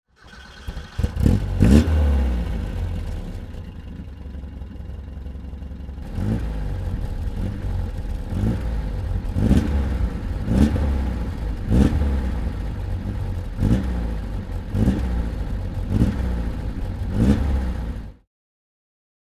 Alfa Romeo 1750 GT Veloce - Starting and idling